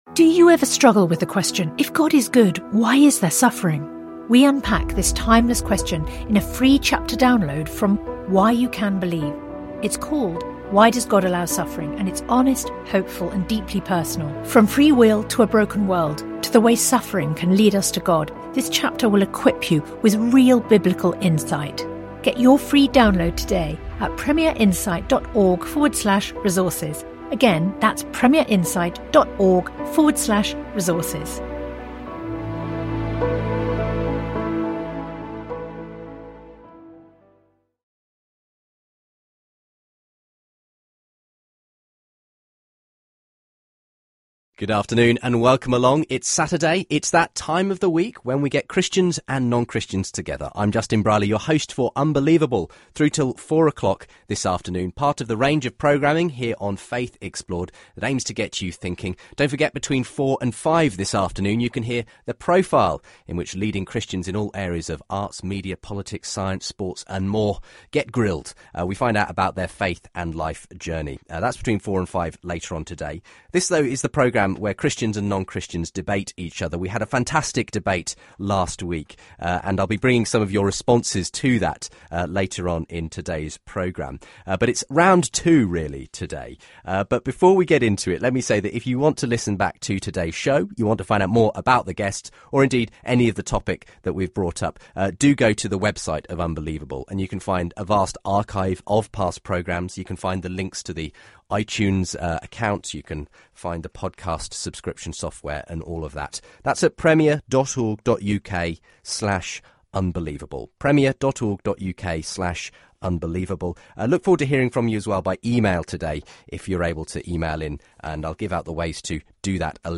For more faith debates visit